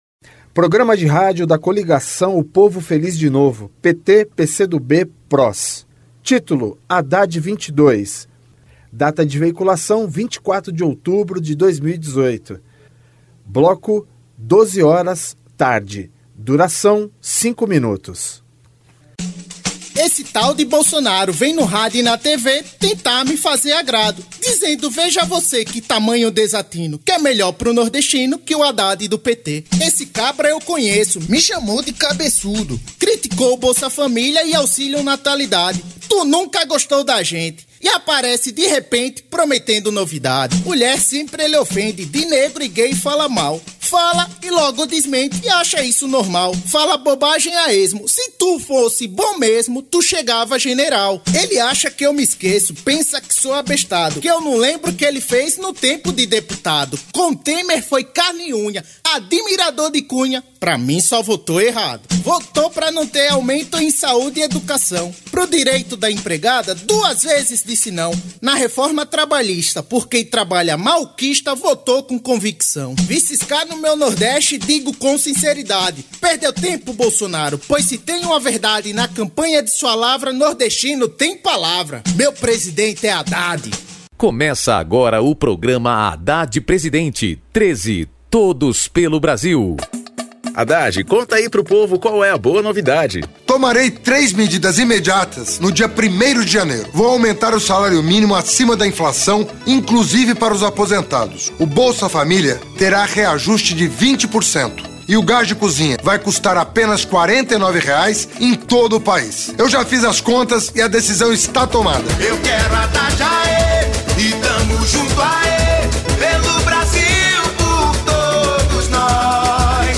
Gênero documentaldocumento sonoro
Descrição Programa de rádio da campanha de 2018 (edição 52), 2º Turno, 24/10/2018, bloco 12hrs.